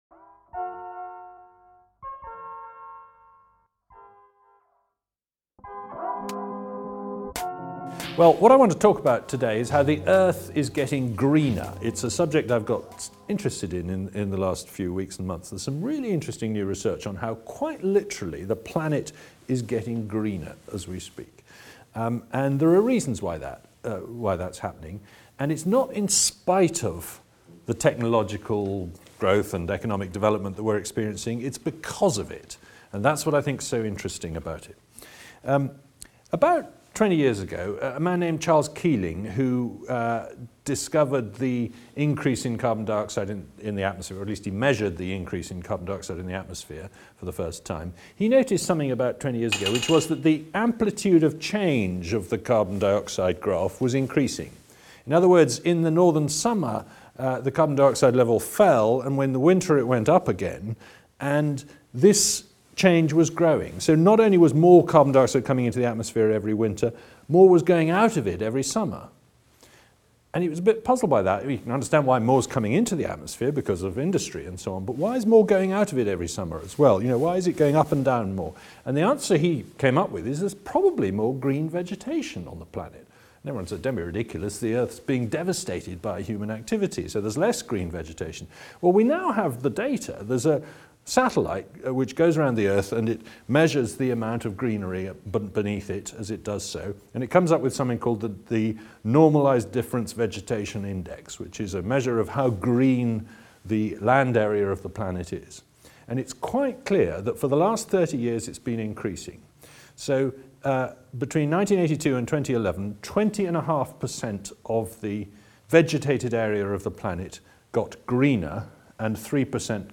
Matt Ridley, author of The Red Queen, Genome, The Rational Optimist and other books, dropped by Reason's studio in Los Angeles last month to talk about a curious global trend that is just starting to receive attention. Over the past three decades, our planet has gotten greener!